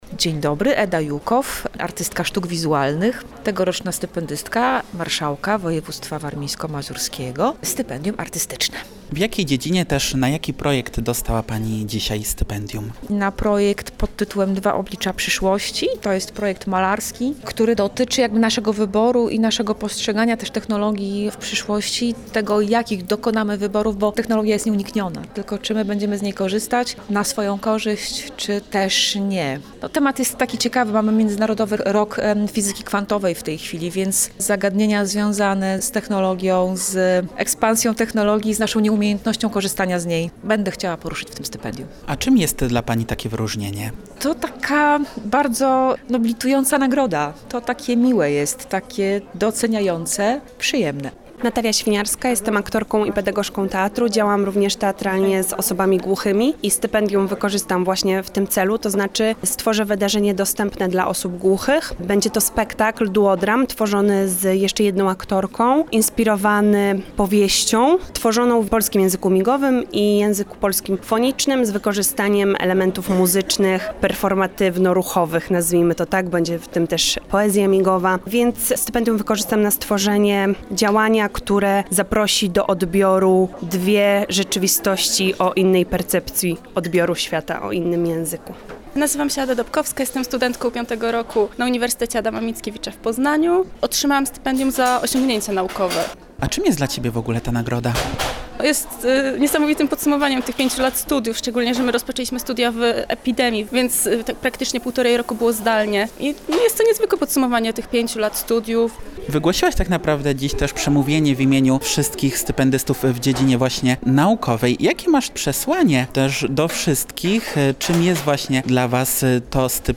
Posłuchajcie rozmów ze stypendystami!